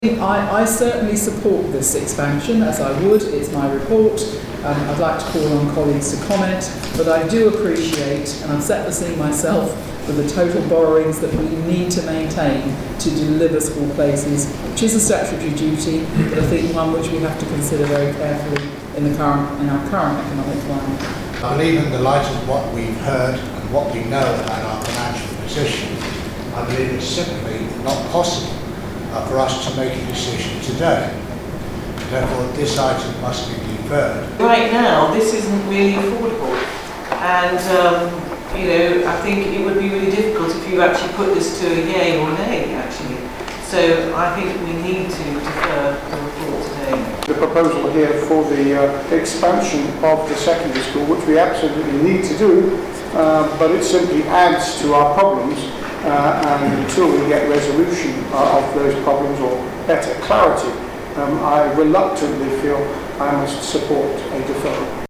Salesian: Linda Kemeny, David Hodge, Helyn Clack and Peter Martin give their views.